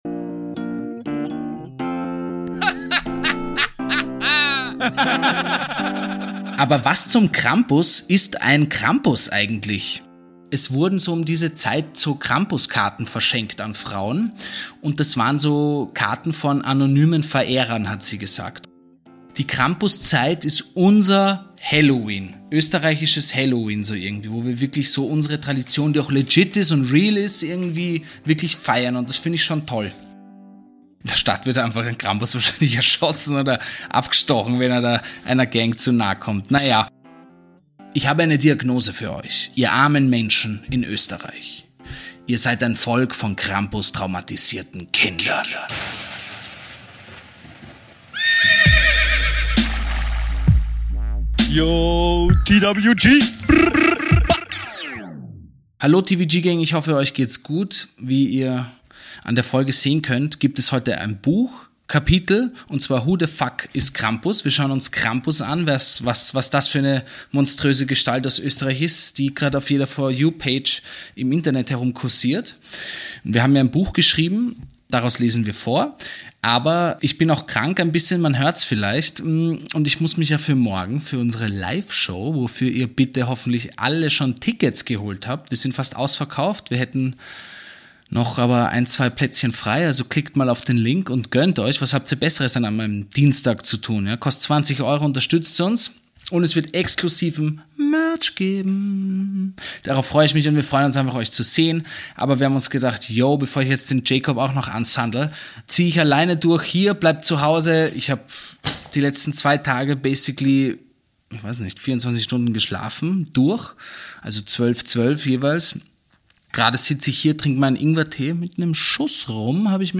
liest ein Kapitel aus ihrem Bestseller